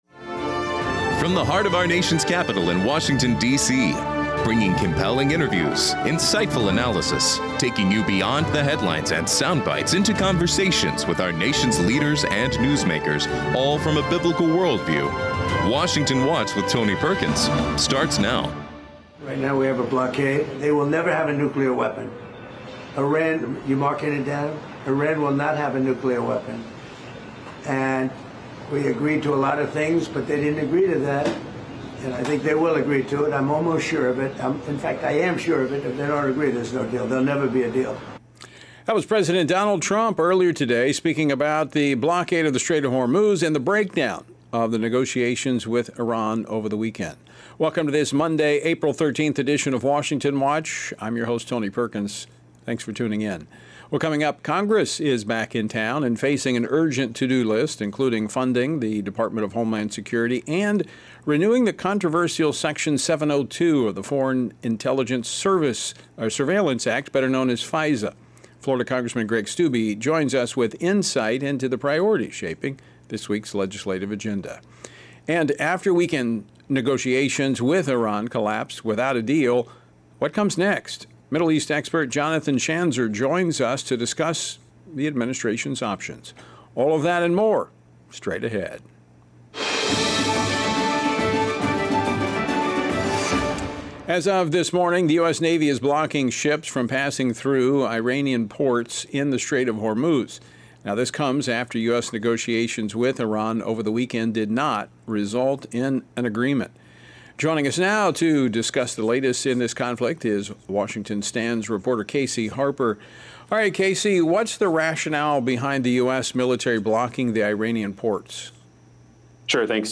Hard hitting talk radio never has been and never will be supported by the main stream in America! Liberty News Radio is taking on the main stream press like never before!